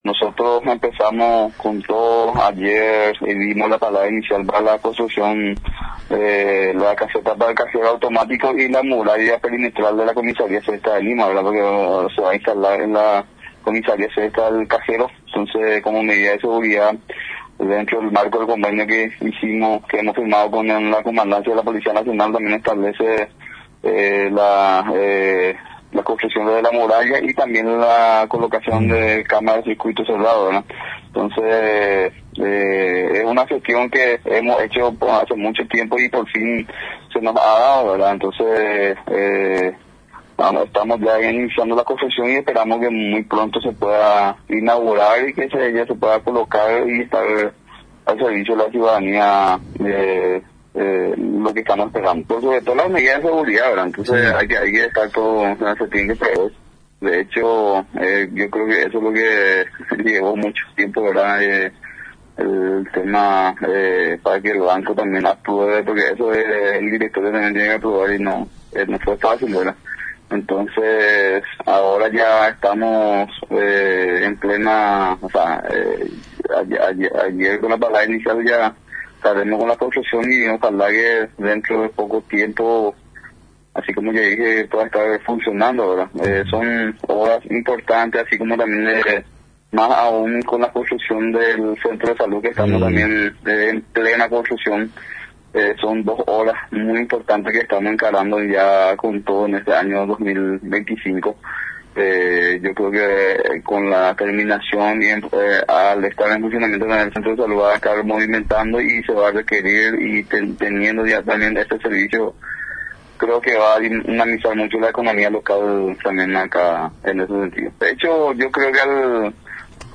ABG.-JUAN-MANUEL-AVALOS-INTENDENTE-DE-LIMA.mp3